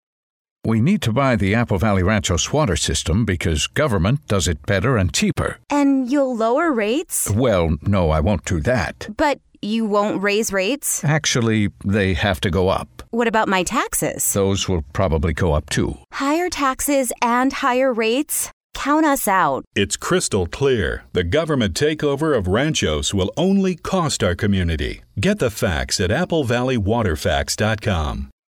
Ranchos Radio Ad #2 (audio)
Ranchos has two great new radio ads.